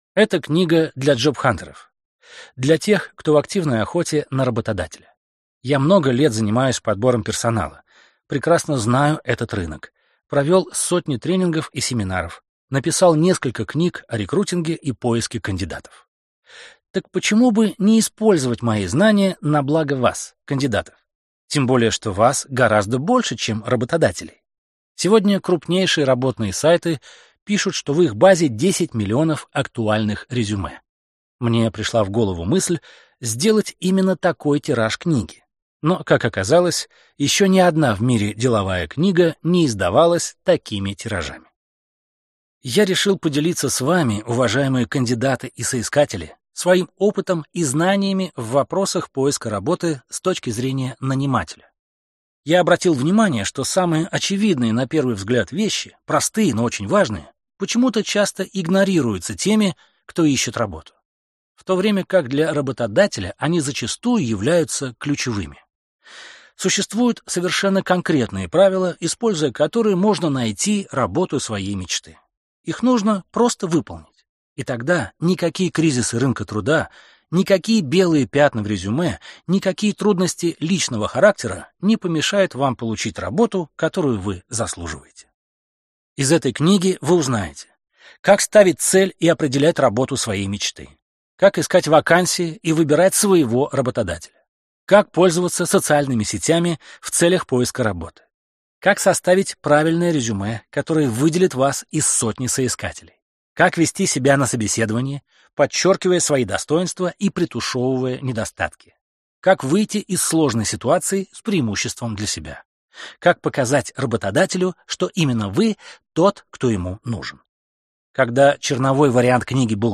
Аудиокнига Я – идеальный кандидат! Справочник джобхантера | Библиотека аудиокниг